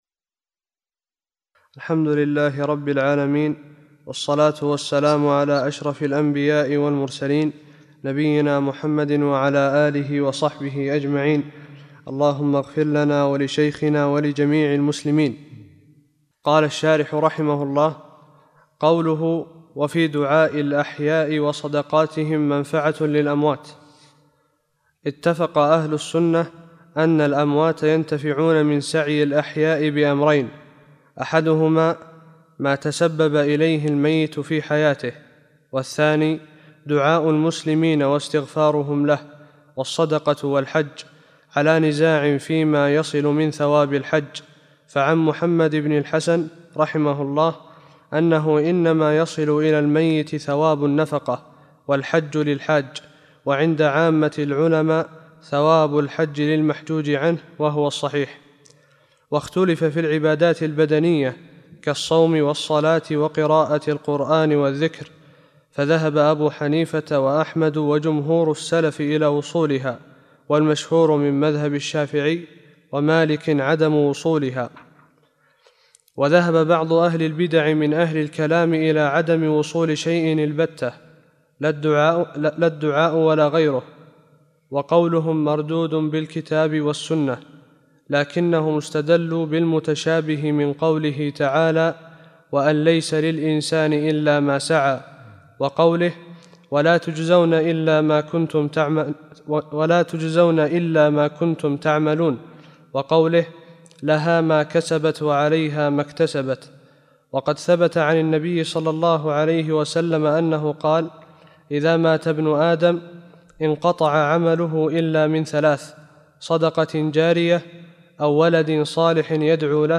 40 - الدرس الأربعون